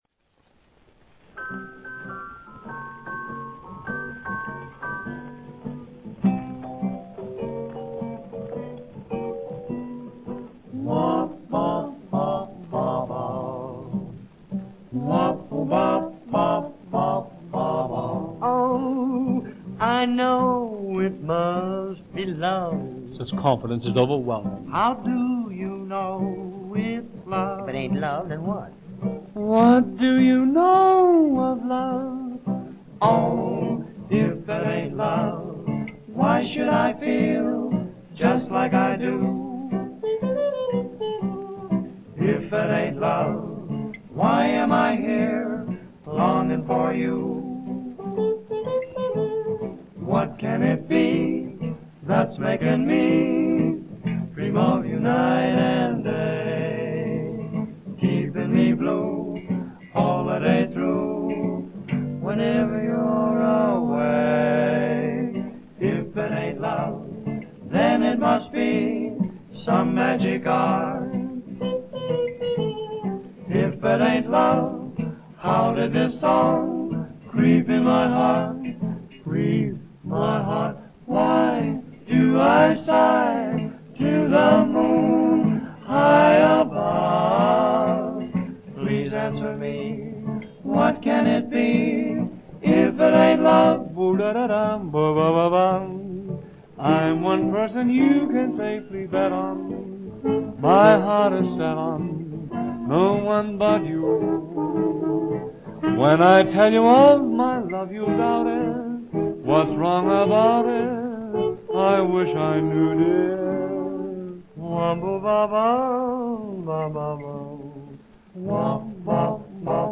vocal
were a vocal trio